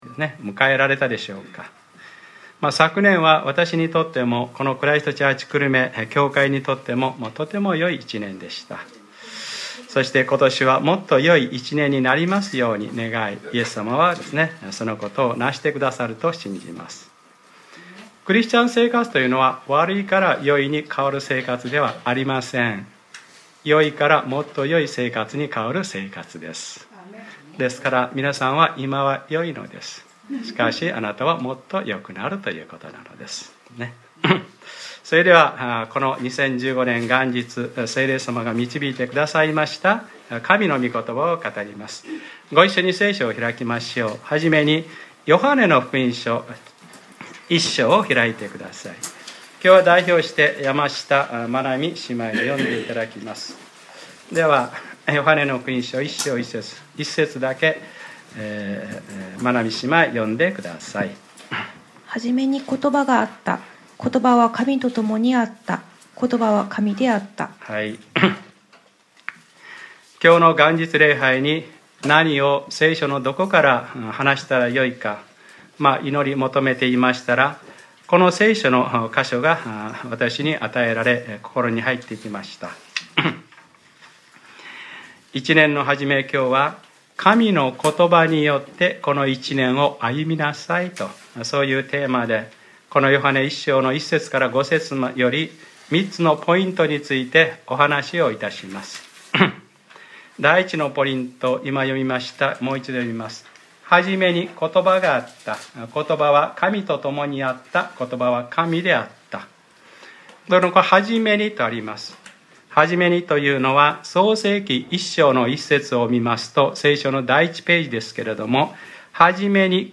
2015年01月01日）礼拝説教 『初めにことばがあった。ことばは神であった。』